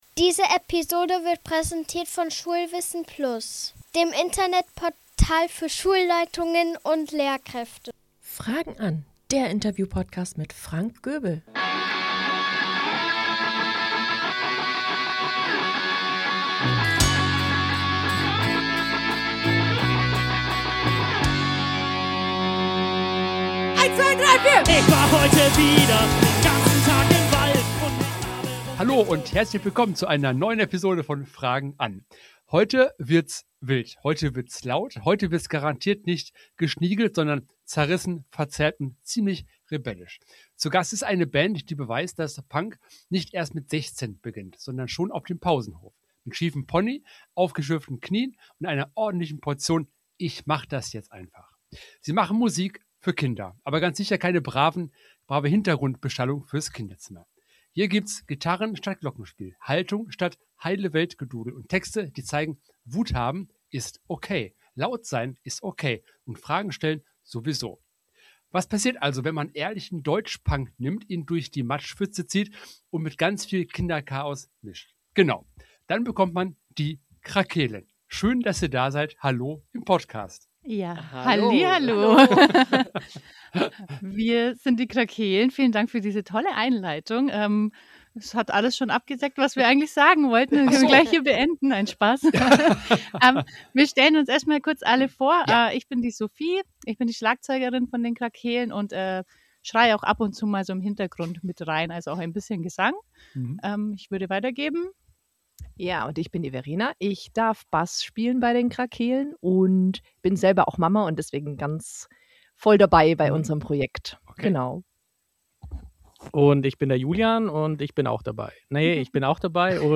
"Die Krakeelen" aus Augsburg sind zu Gast und wir sprechen über ihre Anfänge als Band.